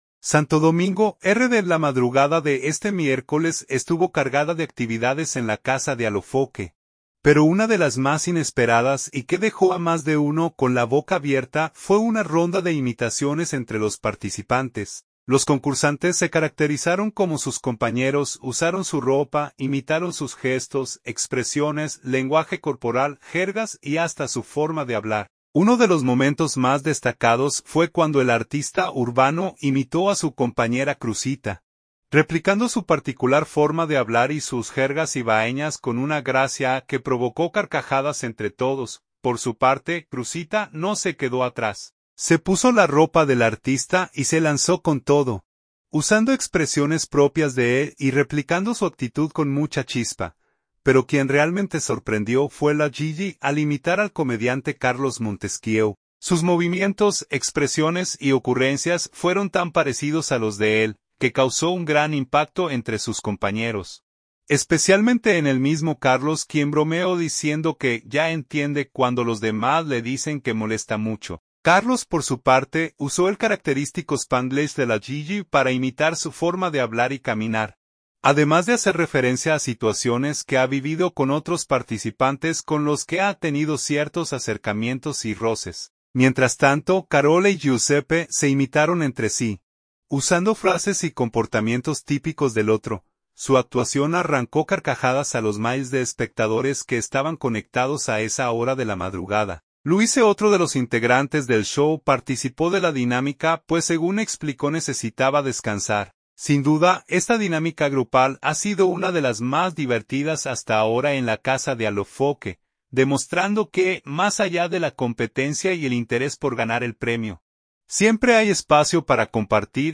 Los concursantes se caracterizaron como sus compañeros: usaron su ropa, imitaron sus gestos, expresiones, lenguaje corporal, jergas y hasta su forma de hablar.